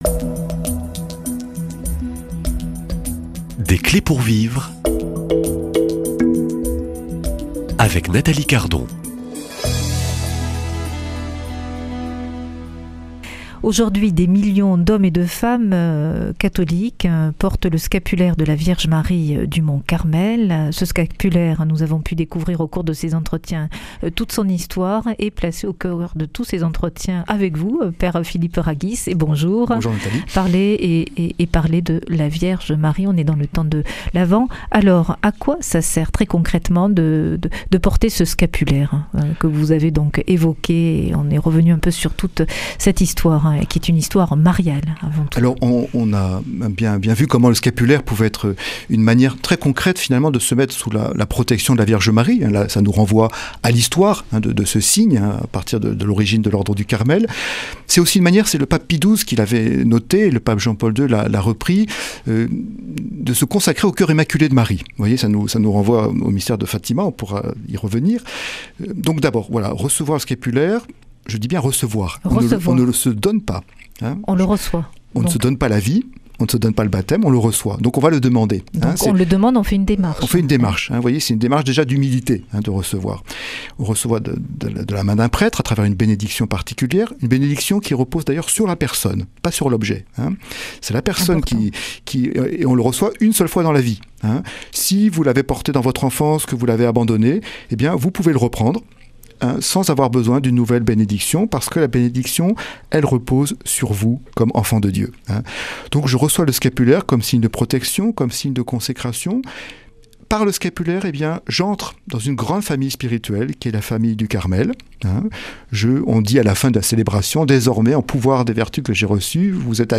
Une émission présentée par